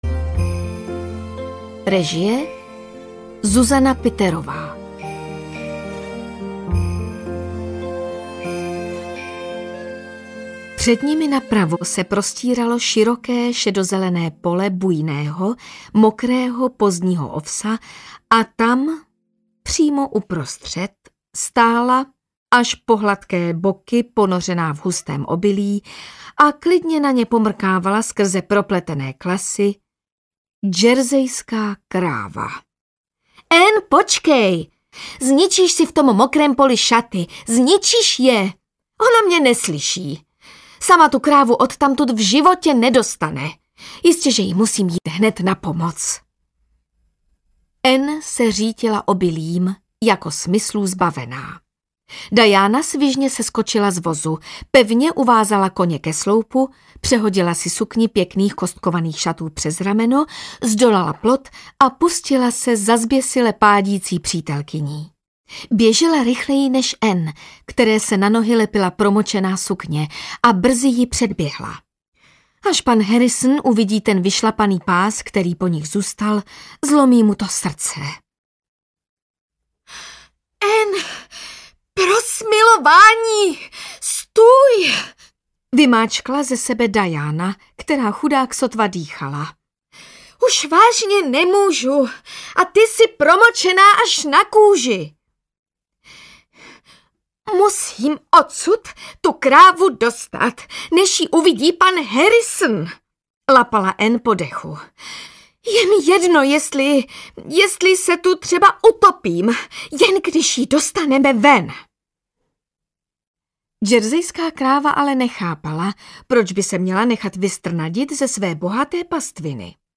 Anne z Avonlea audiokniha
Ukázka z knihy